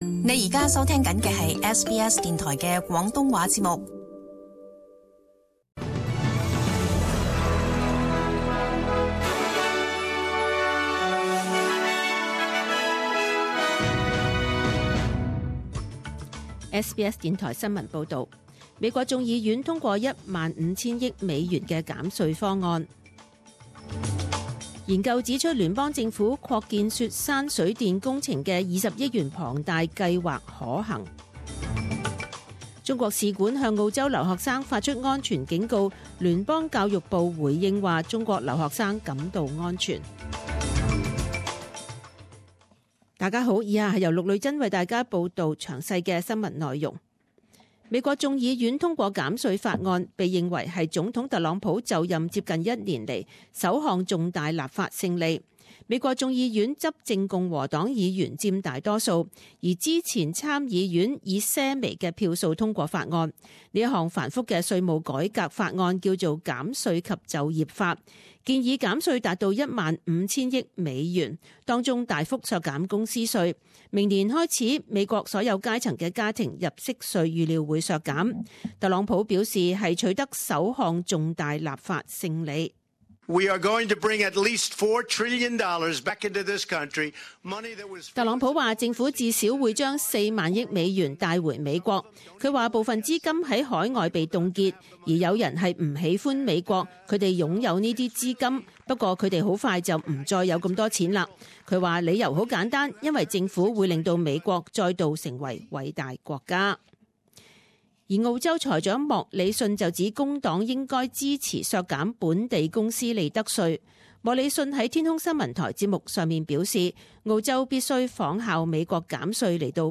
十二月廿一日 [十點鐘新聞 ]
SBS Cantonese 10am news Source: SBS